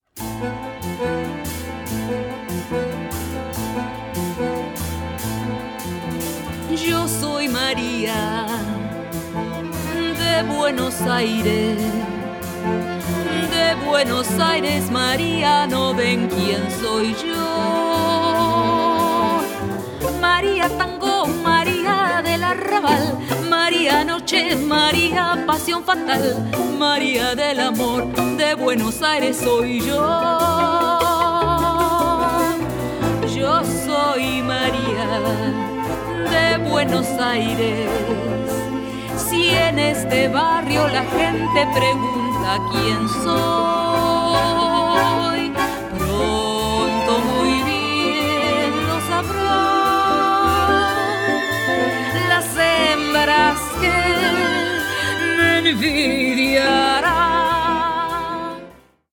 24-bit stereo